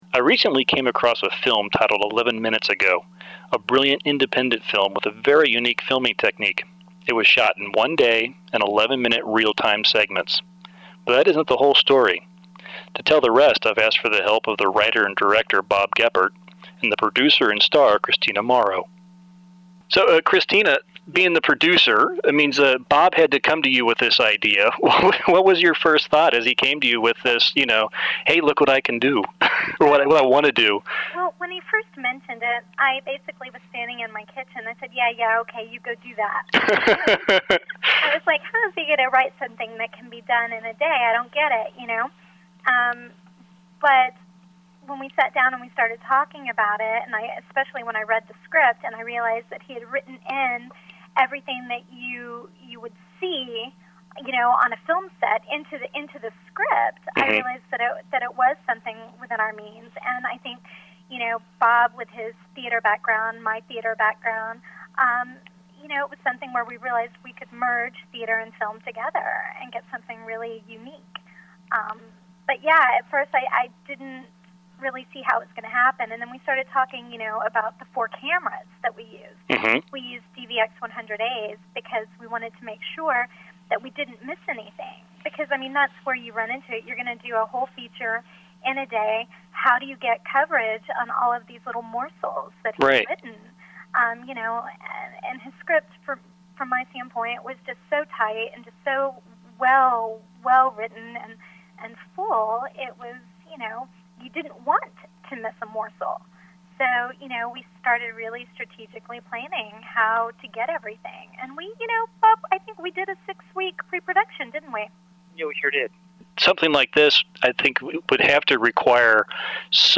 Phone Interview-2009